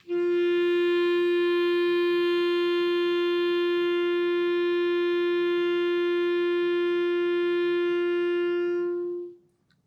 DCClar_susLong_F3_v3_rr1_sum.wav